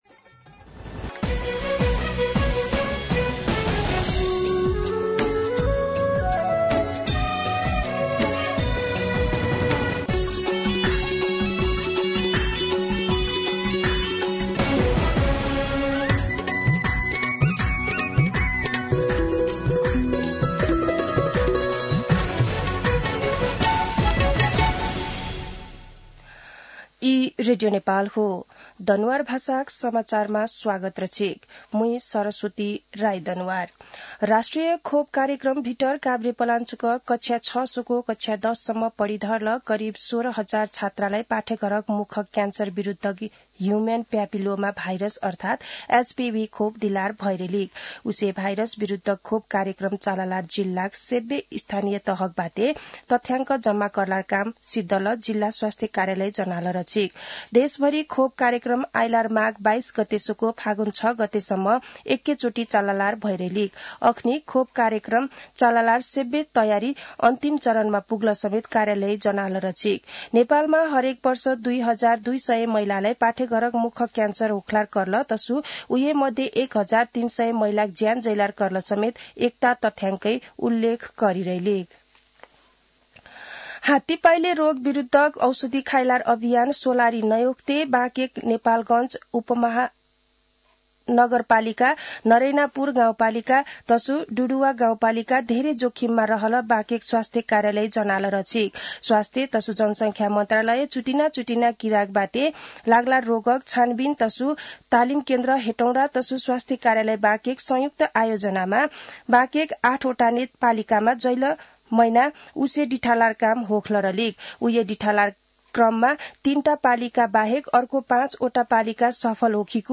दनुवार भाषामा समाचार : २१ पुष , २०८१